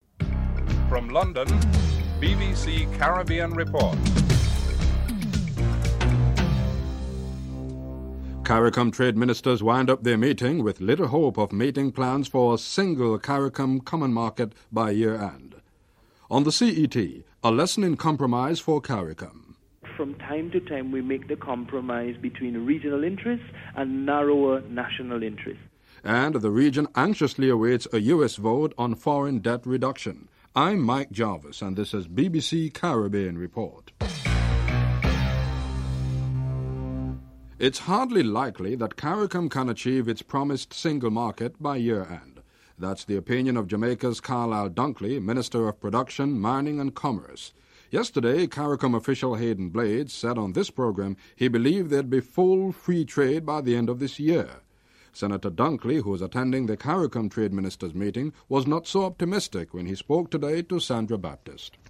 1. Headlines (00:00-00:36)
4. St. Lucia’s Prime Minister John Compton supports Jamaica’s call for a reduction in the CET, calling the move good economic sense during his budget speech.